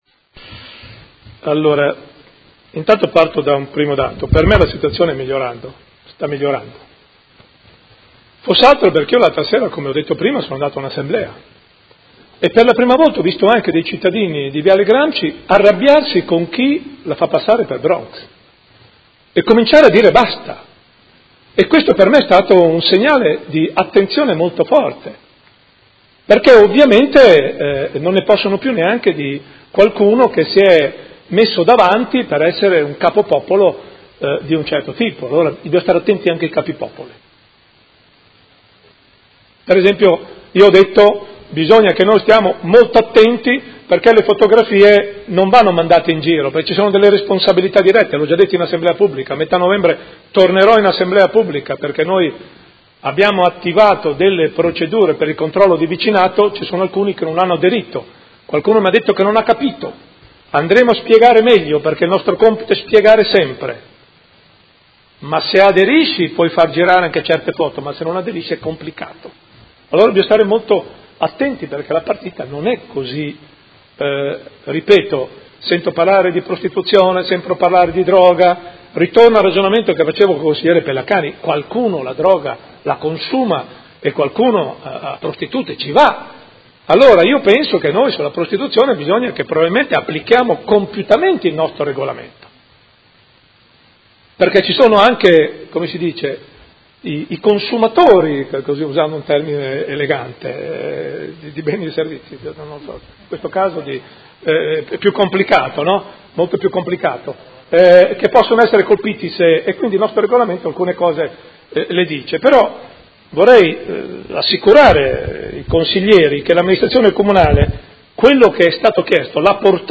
Seduta del 19/10/2017 Risponde. Interrogazione del Gruppo Consiliare Art.1 – MDP avente per oggetto: Persistenza dei gravi problemi di ordine pubblico, sicurezza e vivibilità della zona R-Nord-Viale Gramsci, Parco XXII Aprile e Via Del Mercato.